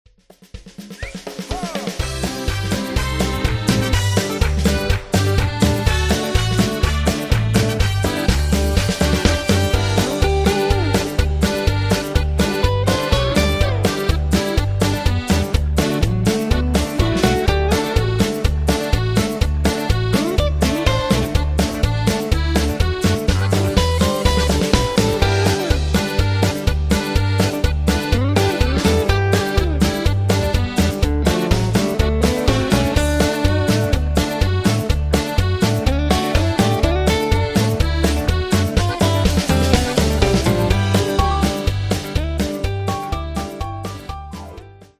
Patter